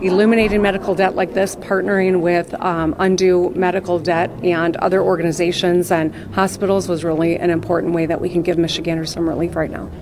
AUDIO: Governor Whitmer announces $144 million in medical debt forgiven for Michiganders